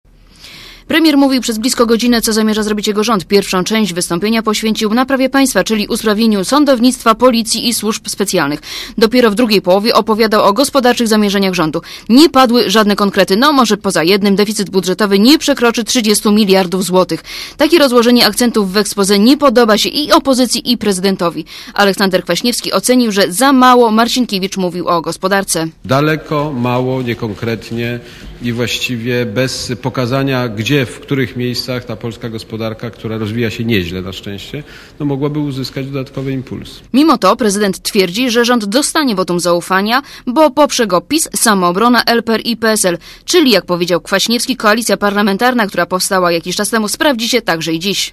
Premier wygłosił w czwartek w Sejmie expose.
marcinkiewicz_-_expose.mp3